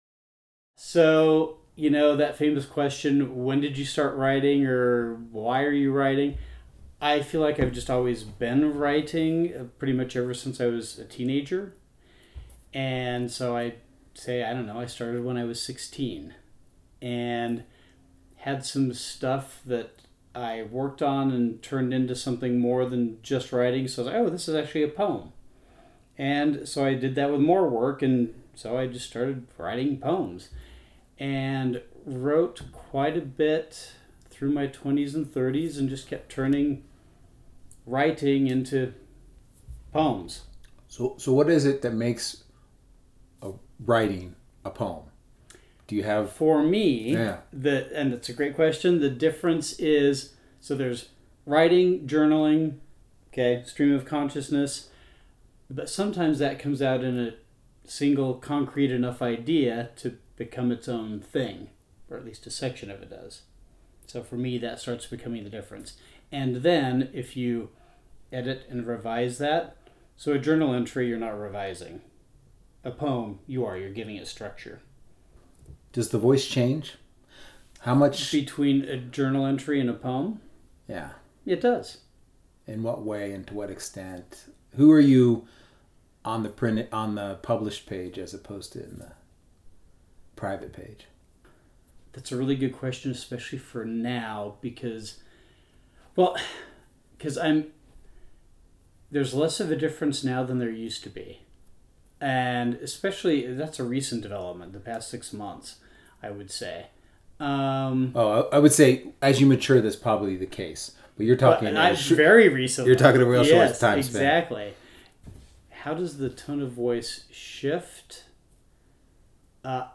This audio portrait is a snapshot of a poet thinking aloud about craft, memory, authenticity, and what it means to make language equal to a life fully observed. http